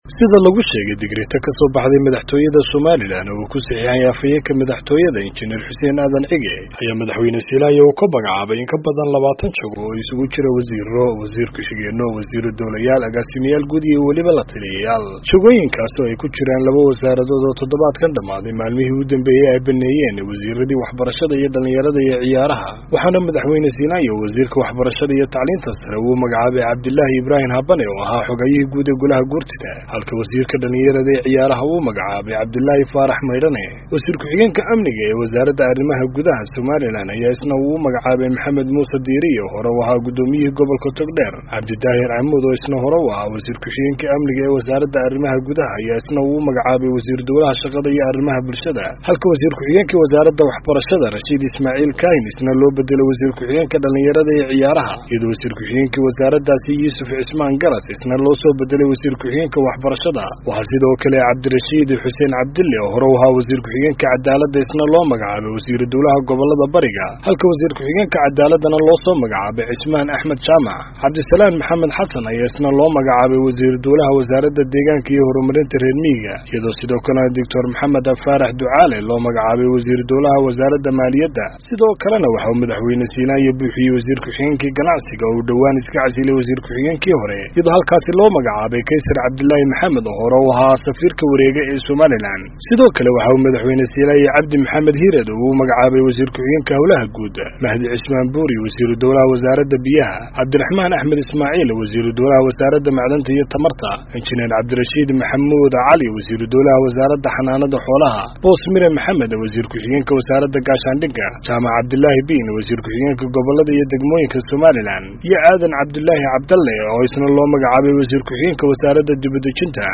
Warbixinta Isku Shaandheynta Wasiirada